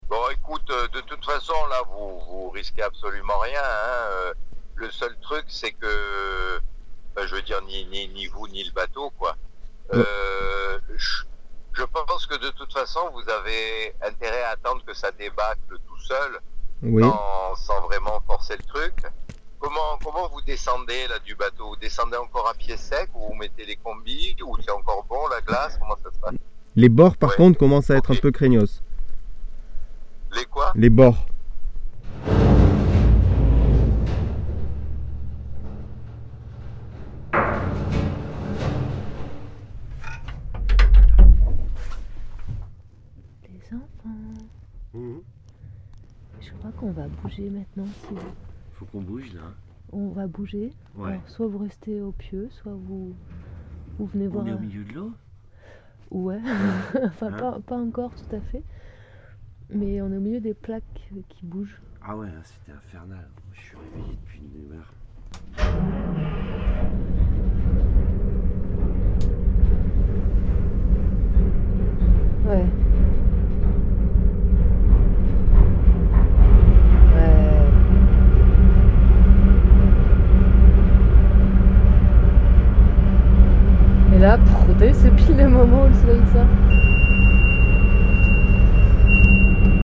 A 2h30 le ciel était clair et la nuit chaude, la lune descendait doucement vers l’ouest mais tout était rempli de bruits, la glace contre la glace et le métal.
Le bateau tanguait et tapait contre la glace avec de plus grands bruits encore. La banquise commença à fendre et se disloquer, le vent reprenait de la force et les fissures se rapprochaient.